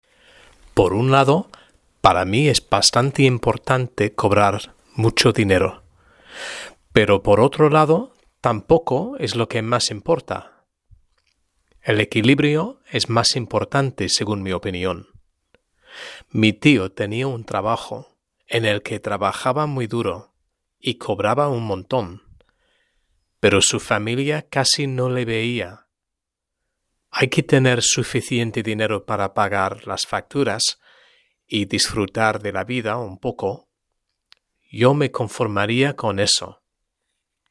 Lectura en voz alta: 1.3 La educación y el trabajo #3 (H)